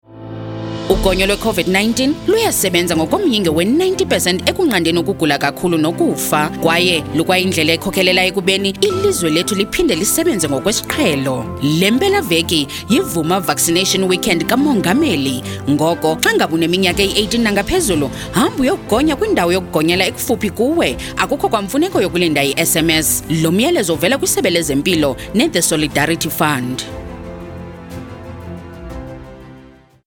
You can listen to the Vooma Vaccine Weekend radio public service announcements by clicking on the links below: